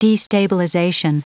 Prononciation du mot destabilization en anglais (fichier audio)
Prononciation du mot : destabilization